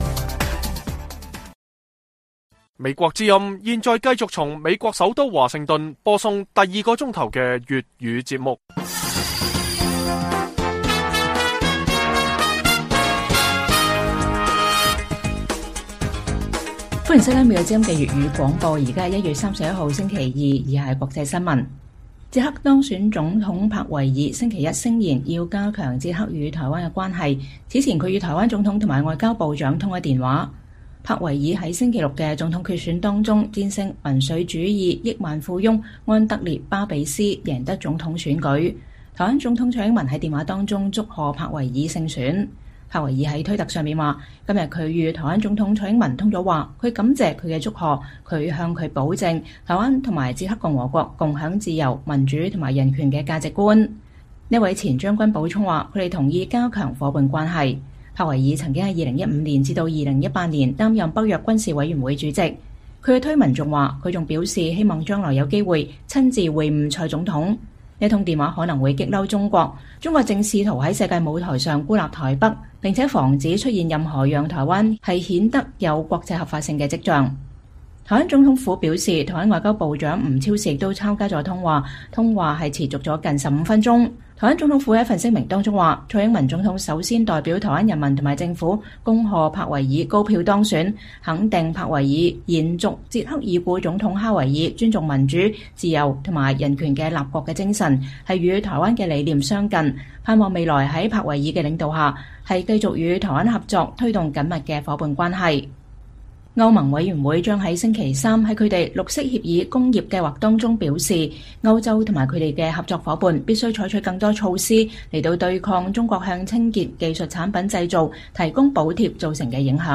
粵語新聞 晚上10-11點：捷克當選總統帕維爾與台灣總統蔡英文通話